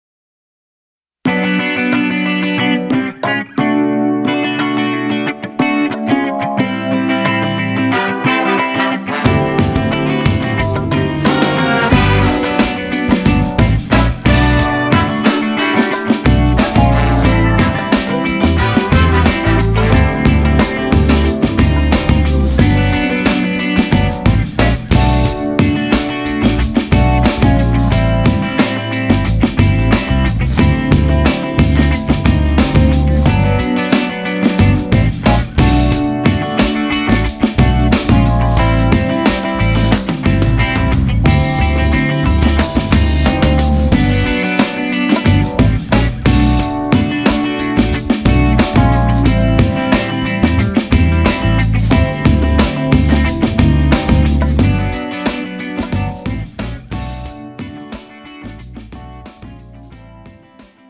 장르 가요 구분 Premium MR